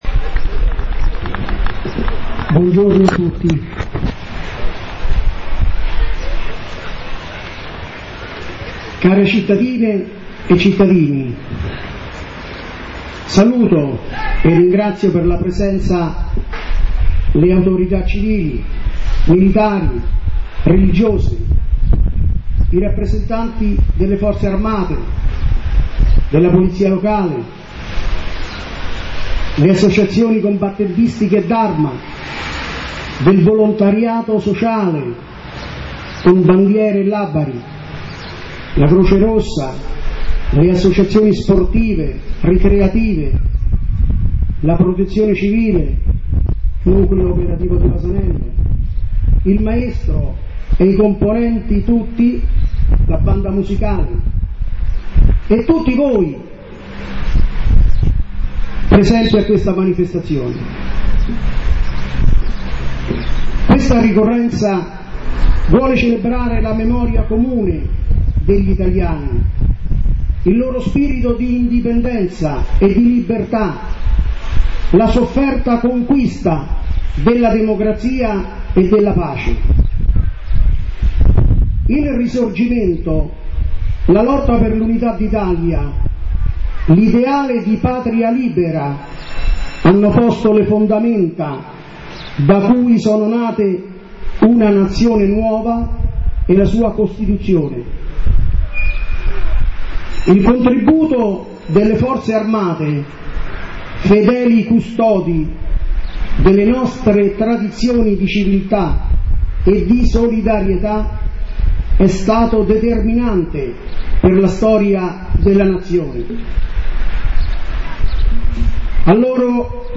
Commemorazione del 4 Novembre
Il Discorso del Sindaco di Vasanello Sig.r Primo Paolocci